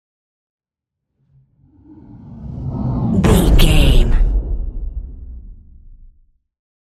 Cinematic whoosh to hit deep
Sound Effects
Atonal
dark
futuristic
intense
tension